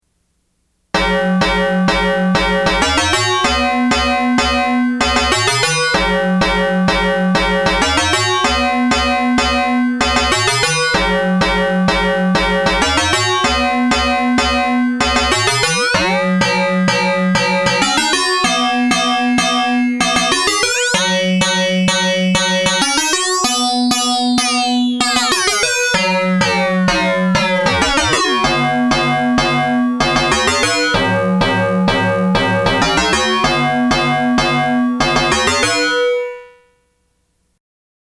sample four: two oscillator sequence on the input with standard vca/envelope.